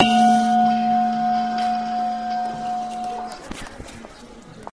宜家物语铃声
描述：钟声般的声音 一个宜家的烟灰缸用手指轻轻地敲击（wtf），而人们走来走去，进行复杂的心理操作来调整成本...
标签： 钟形物体 声音 宜家 环境 记录 烟灰缸 移动
声道立体声